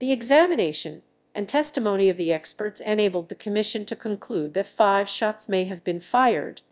1. The sample generated with torchaudio's pipeline
TACOTRON2_WAVERNN_PHONE_LJSPEECH_v2.wav